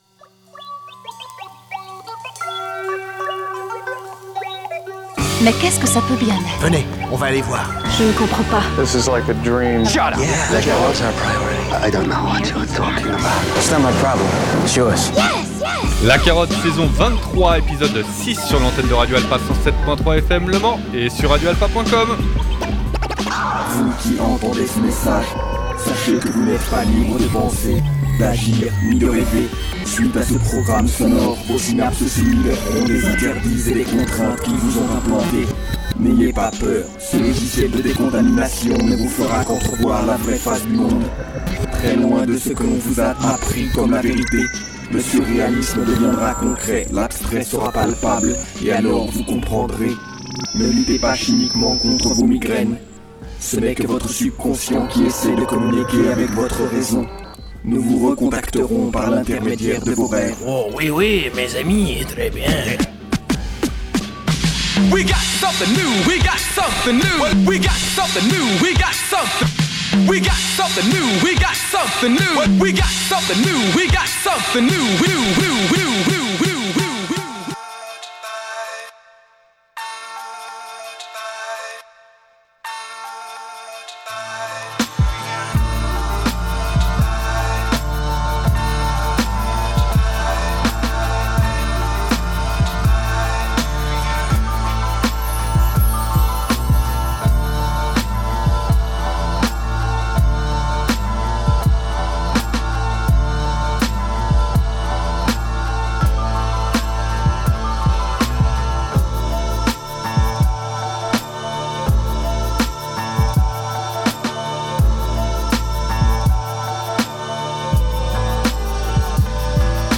News #2 // Le boom bap a pris ses quartiers d'automne dans cet épisode en s'adjugeant la part belle de celui-ci mais il n'empêche qu'il y a quelques directions opposées qui sont offertes aux oreilles curieuses et dont voici la playlist
News #2